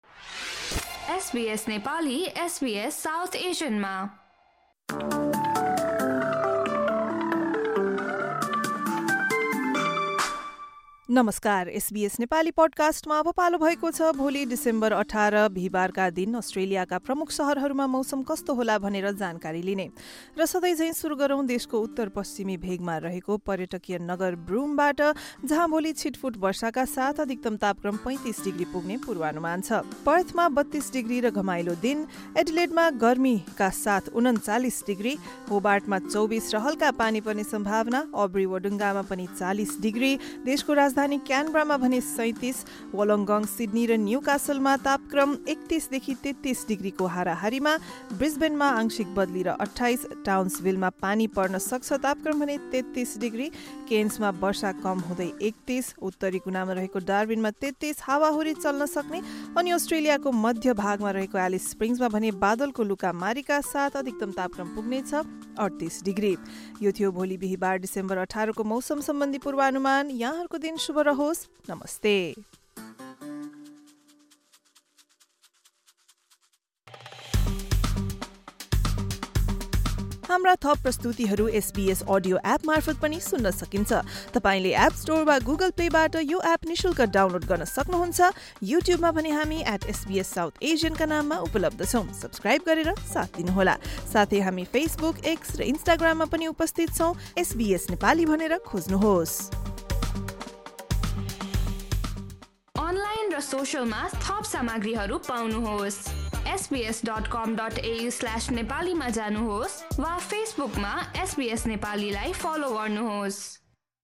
Weather update for major cities across Australia in Nepali. This update features tomorrow’s forecast for the following cities: Broome, Perth, Adelaide, Melbourne, Hobart, Albury-Wodonga, Sydney, Newcastle, Brisbane, Townsville, Cairns, Darwin and Alice Springs.